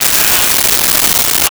Alien Wierdness.wav